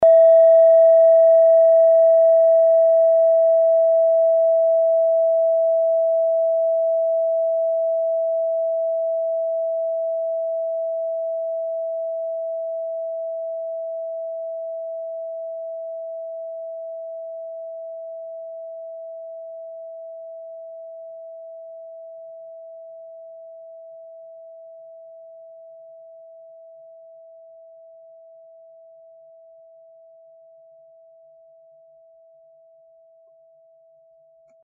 Kleine Klangschale Nr.3
Klangschale-Durchmesser: 11,5cm
Sie ist neu und ist gezielt nach altem 7-Metalle-Rezept in Handarbeit gezogen und gehämmert worden.
Die 37. Oktave dieser Frequenz liegt bei 241,56 Hz. In unserer Tonleiter liegt dieser Ton nahe beim "H".